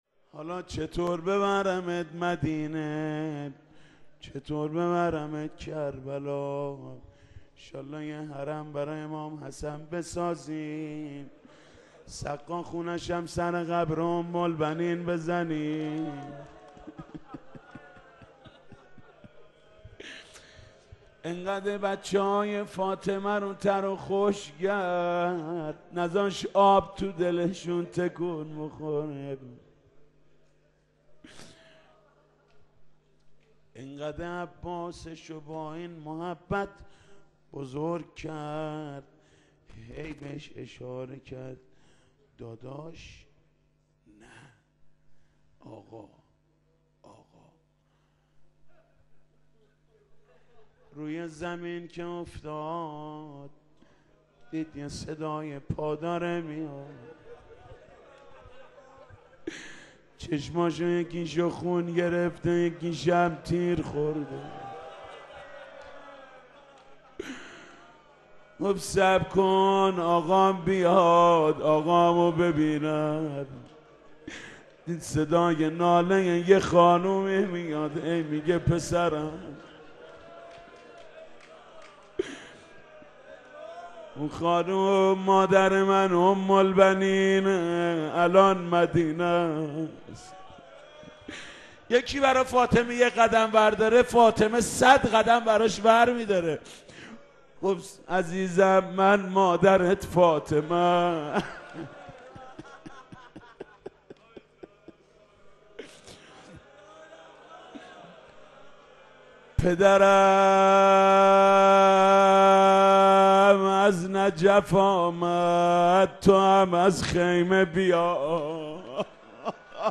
حاج محود کریمی -روضه ام البنین- قسمت چهارم-قزوین-آستان مقدس چهارانبیا-موسسه پرچمدار.mp3